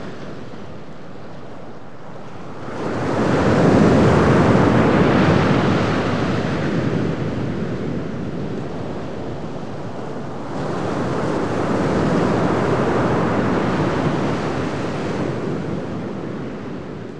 seanight.wav